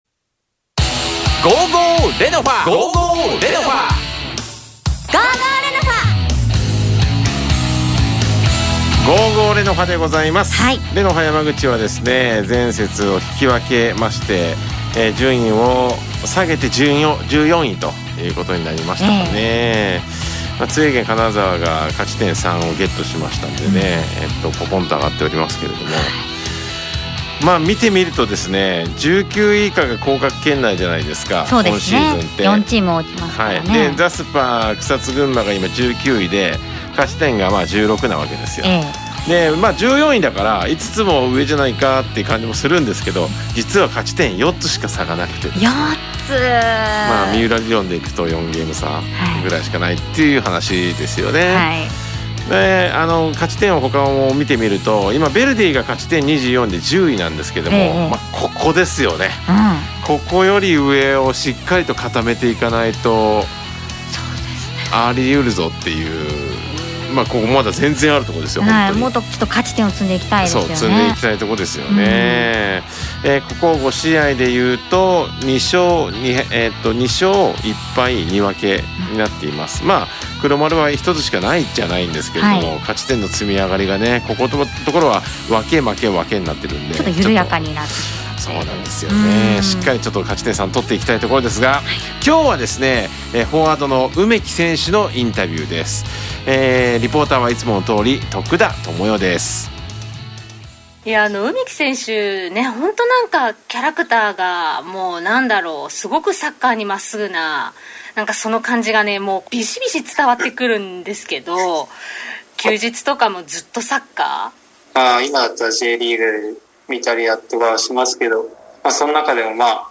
※インタビューは通信会議アプリを使って収録したものです。